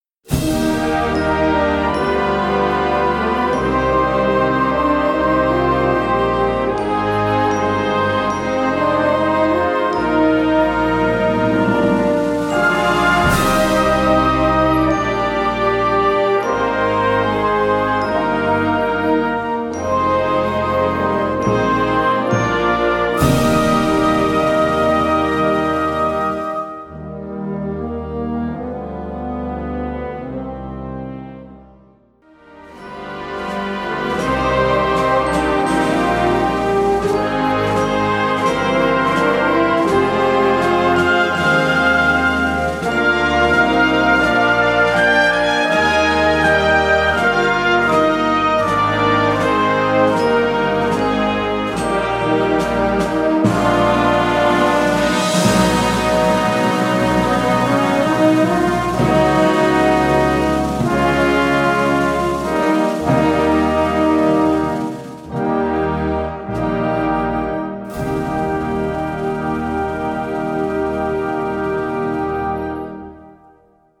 Category Concert/wind/brass band
Subcategory Chorales, ballads, lyrical music
Instrumentation Ha (concert/wind band)